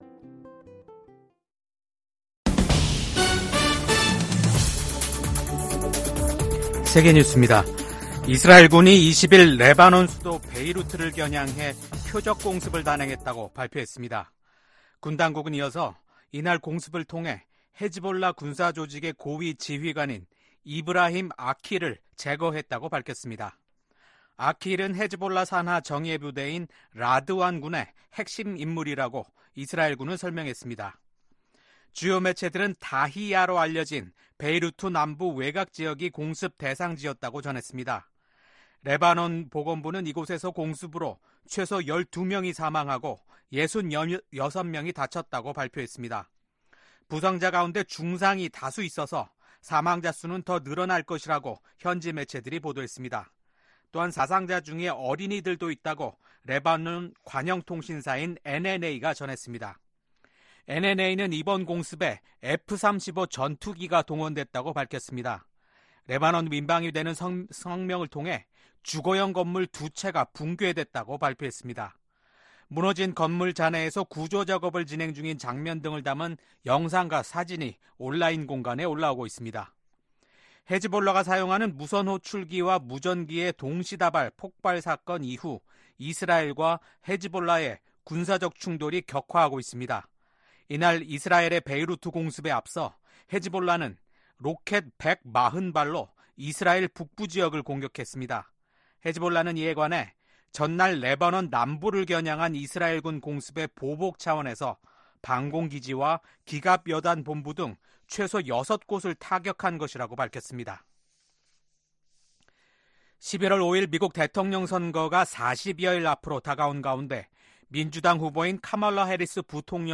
VOA 한국어 아침 뉴스 프로그램 '워싱턴 뉴스 광장' 2024년 9월 21일 방송입니다. 미국 정부가 북-러 간 불법 자금 거래에 관여한 러시아 회사 5곳 및 국적자 1명을 전격 제재했습니다.